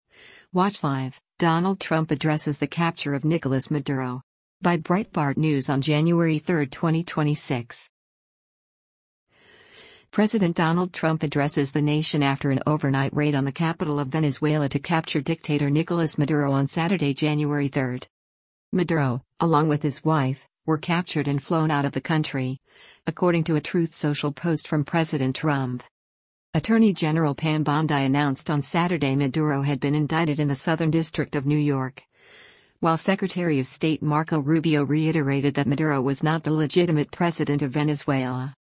President Donald Trump addresses the nation after an overnight raid on the capital of Venezuela to capture dictator Nicolas Maduro on Saturday, January 3.